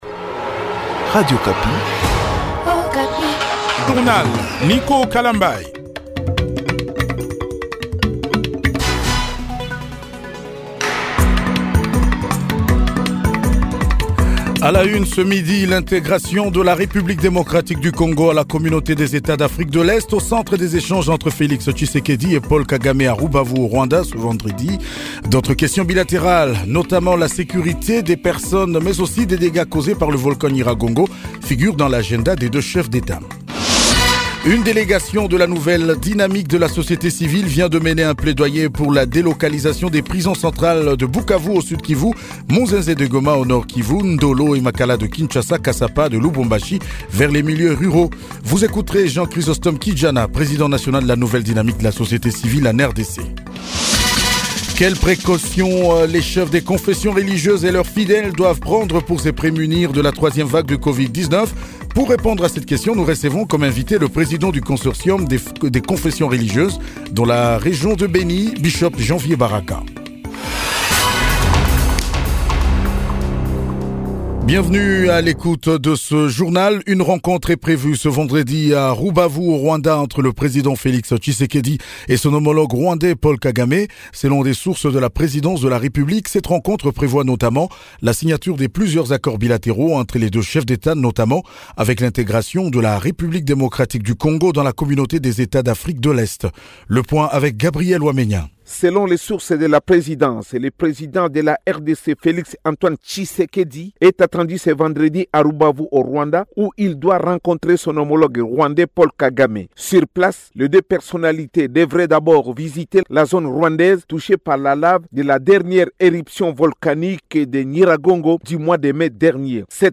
JOURNAL MIDI DU 25 JUIN 2021